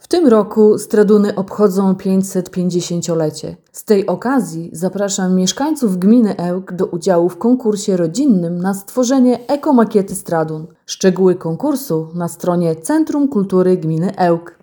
Do wspólnej zabawy Centrum Kultury Gminy Ełk zaprasza całe rodziny, mówi Diana Dawidowska, sołtys Stradun.